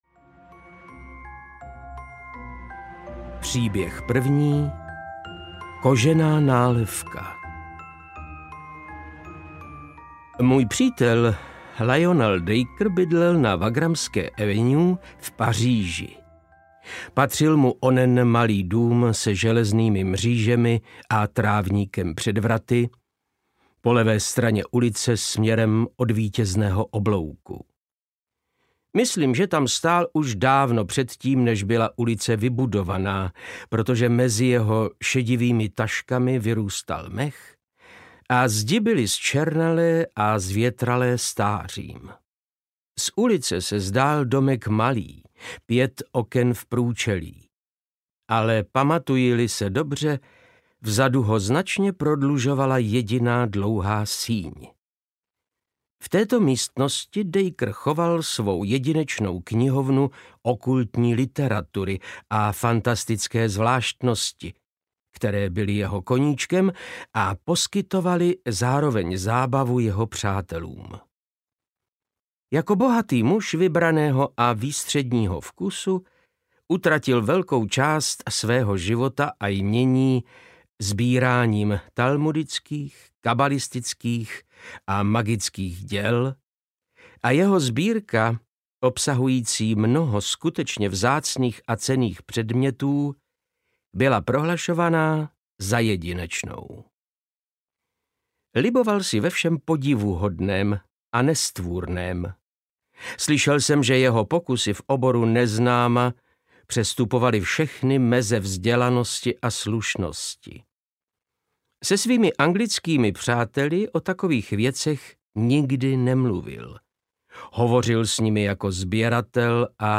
Tajuplné příběhy audiokniha
Ukázka z knihy
• InterpretVáclav Knop